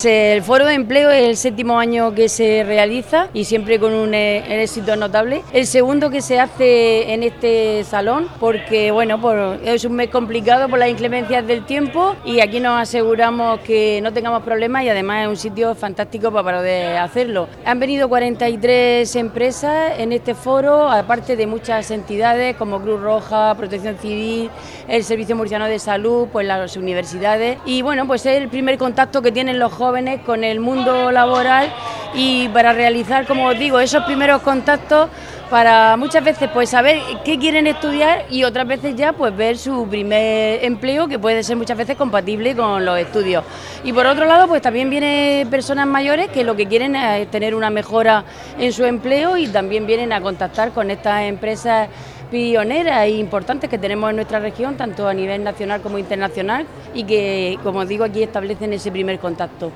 Declaraciones de la alcaldesa de Alcantarilla, Paquí Terol.